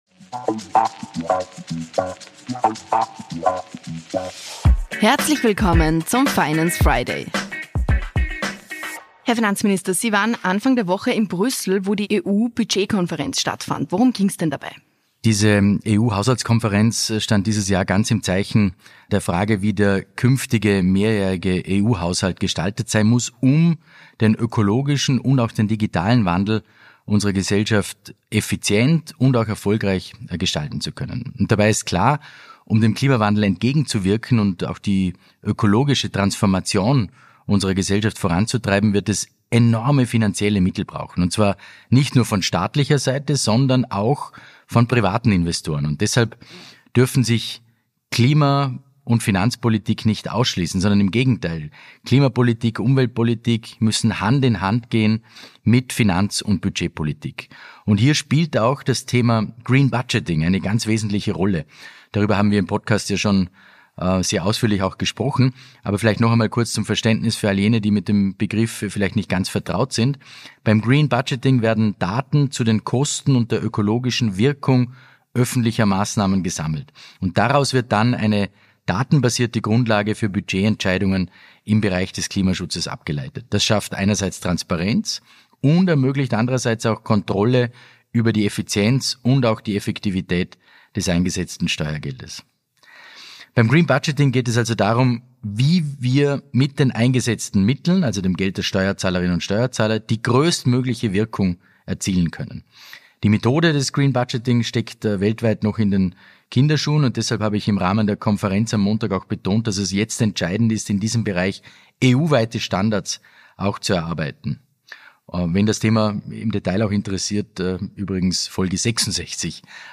gesprochen hat, erzählt Finanzminister Magnus Brunner in der